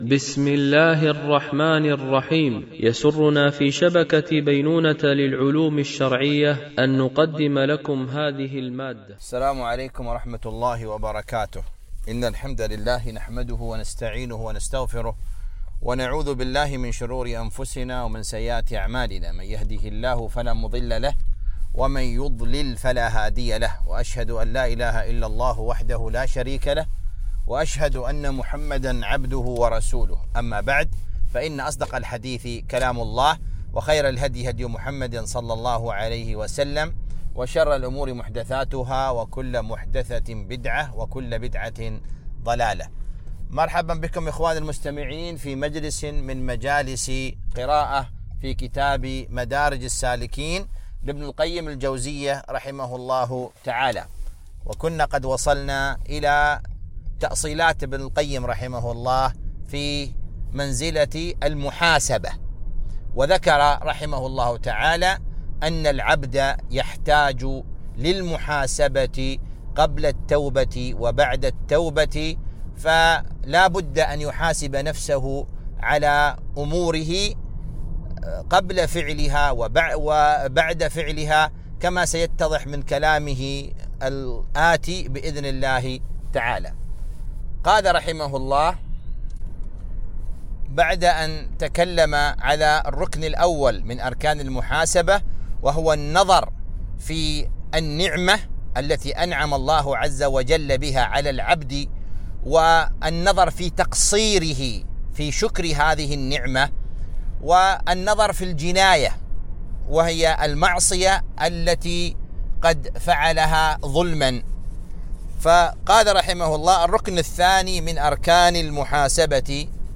قراءة من كتاب مدارج السالكين - الدرس 22